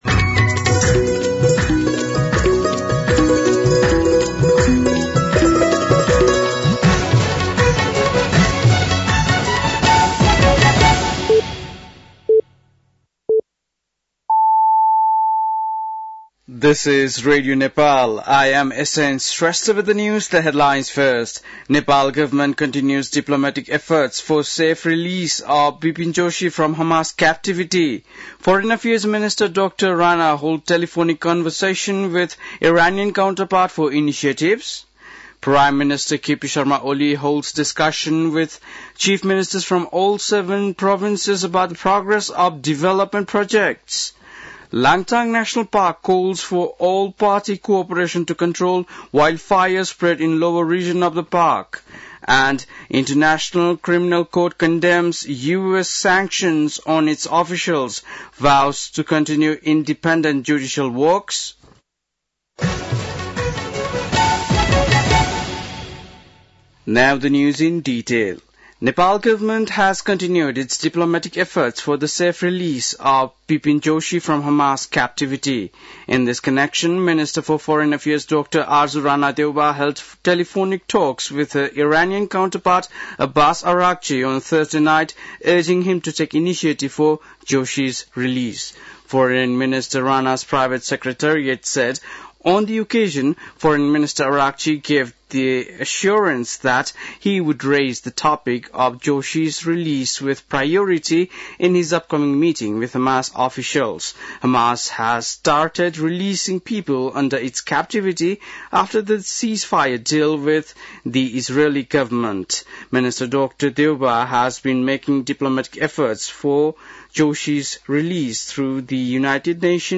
बेलुकी ८ बजेको अङ्ग्रेजी समाचार : २६ माघ , २०८१
8-PM-English-News-10-25.mp3